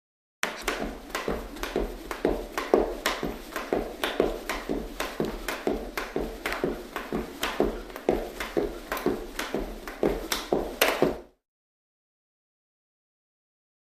Cardiovascular Exercise; Jumping Rope. Steady.